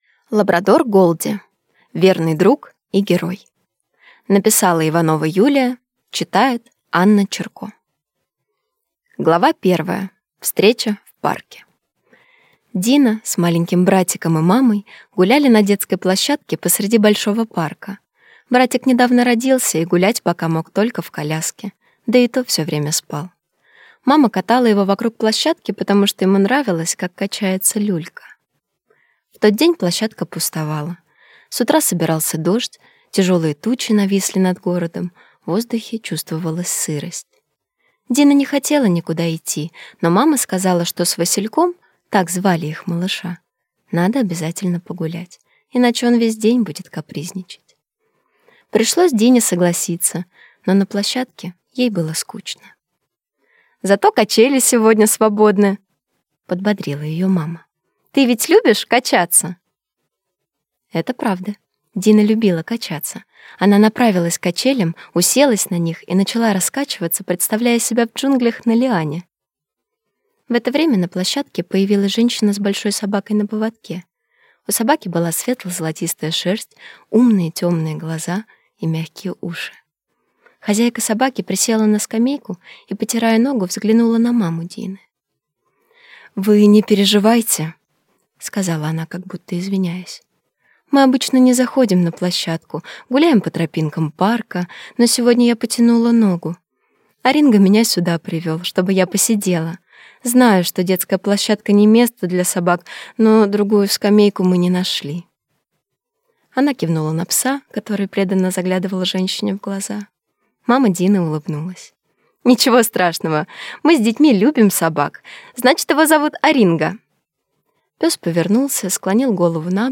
Аудиокнига Лабрадор Голди. Верный друг и герой | Библиотека аудиокниг